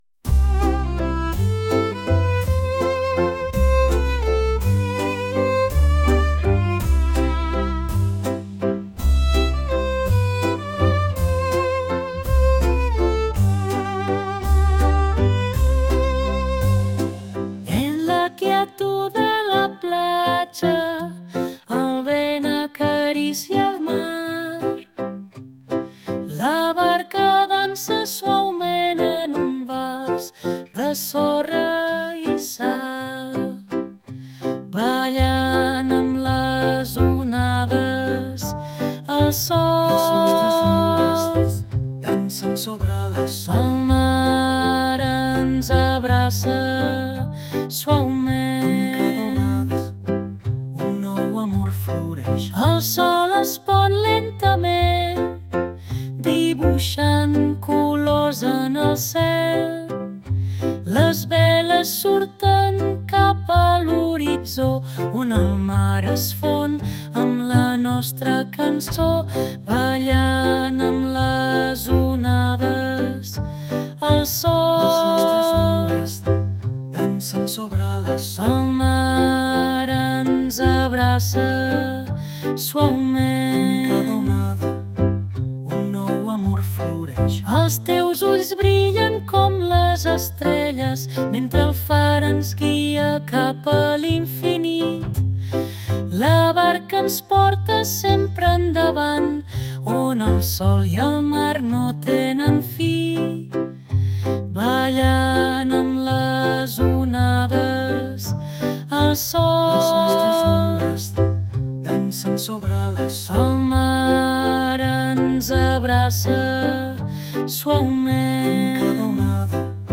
vals romàntic